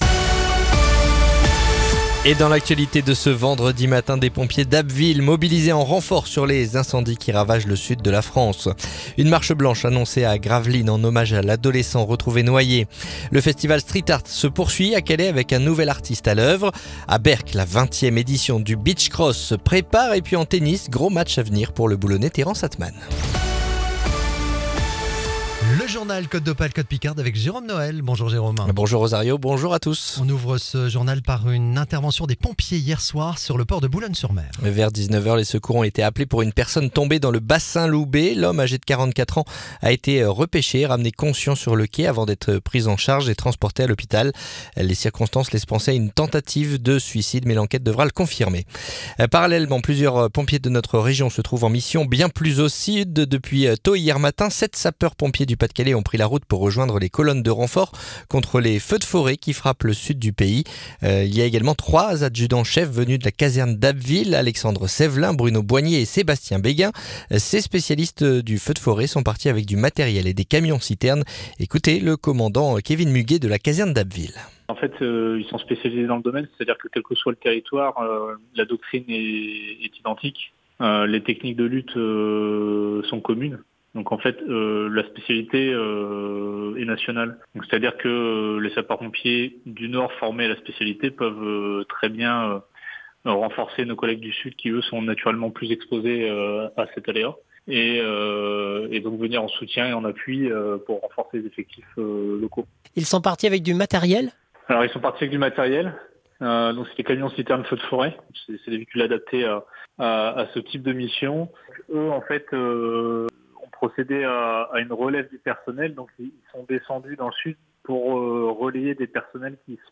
Le journal du vendredi 8 août